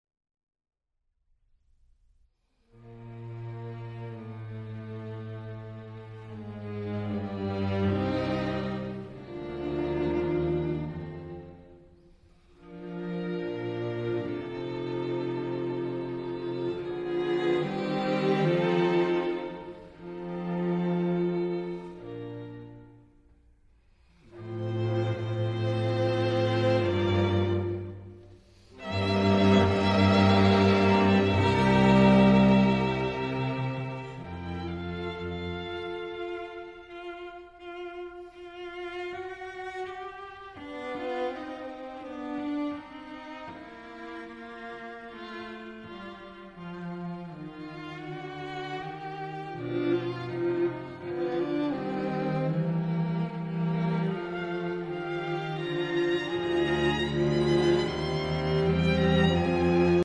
Great Classical Music